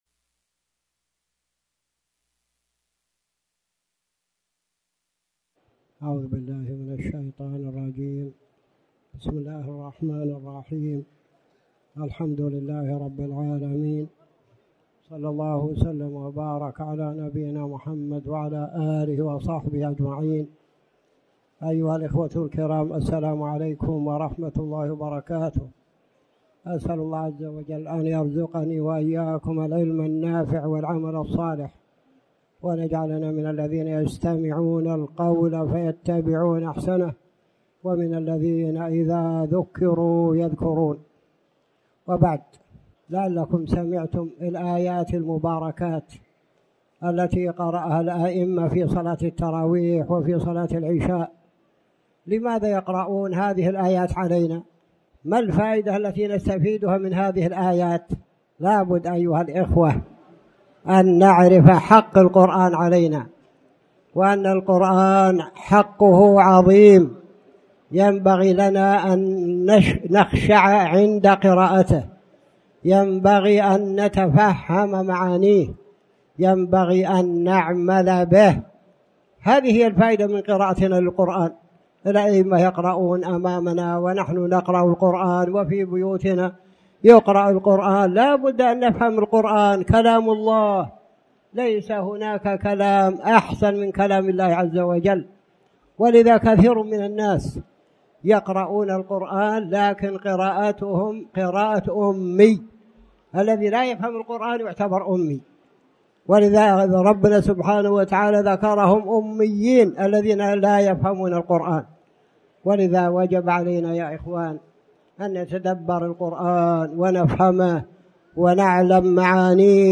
تاريخ النشر ٧ رمضان ١٤٣٩ هـ المكان: المسجد الحرام الشيخ